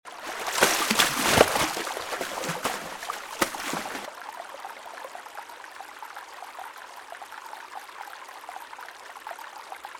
water03.mp3